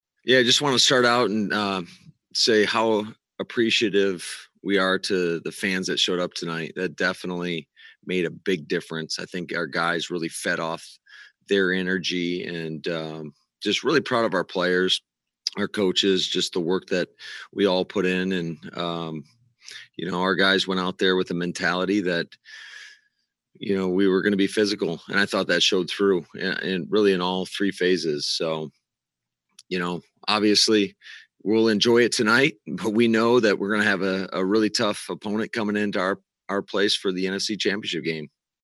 That’s where Matt LaFleur had to start with his post game press conference.